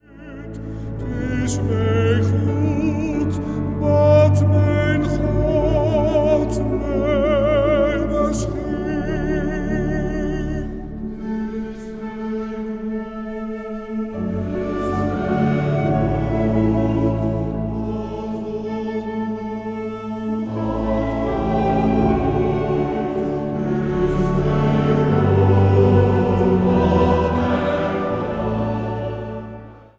bariton
fluit
piano
orgel.
Zang | Mannenkoor